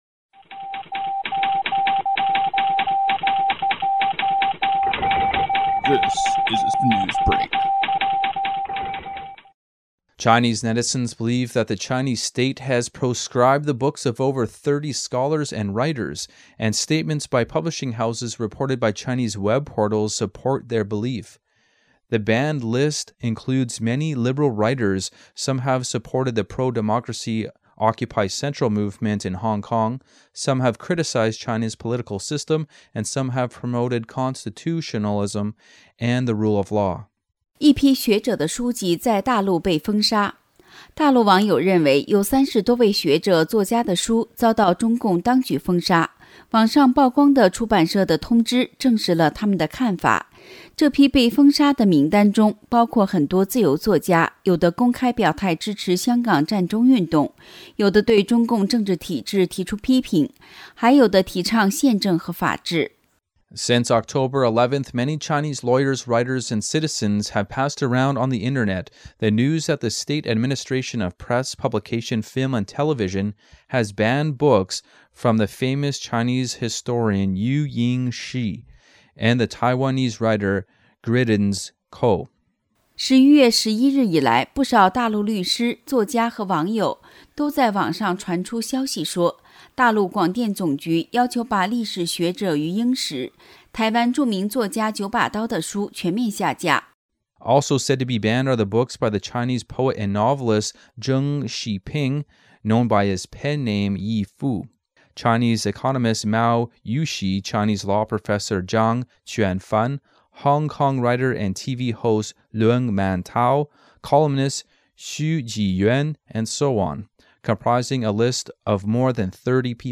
Type: News Reports
Oct_24_2014_books_banned_in_China.mp3 5,016k
128kbps Mono